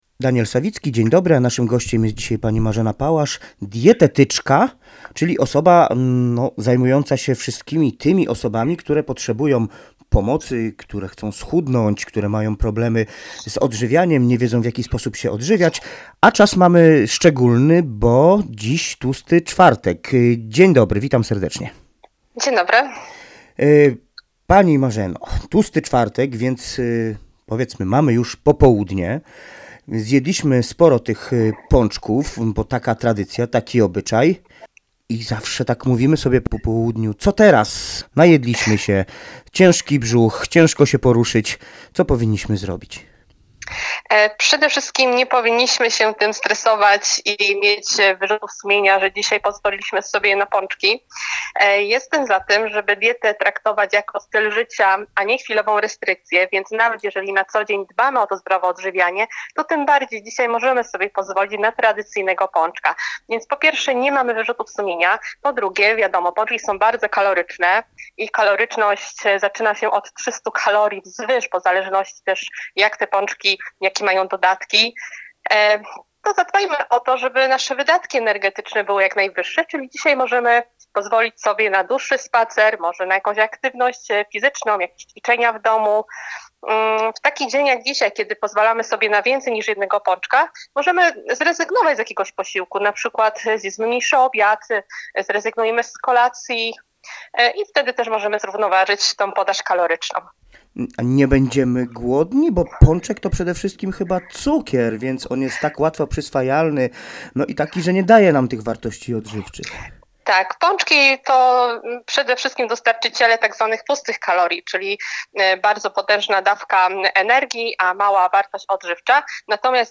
Z dietetyczką